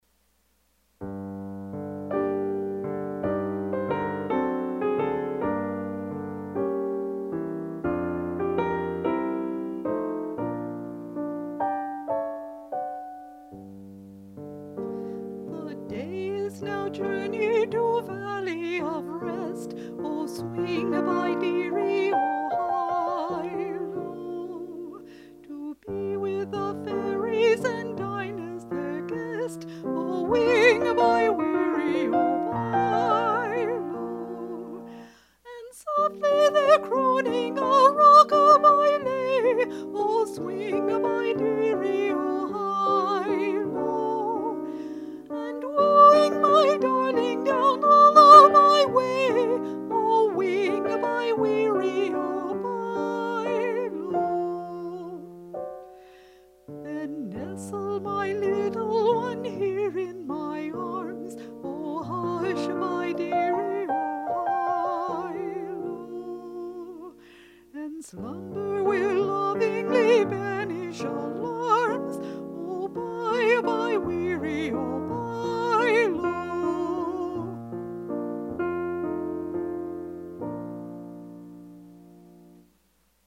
voice and piano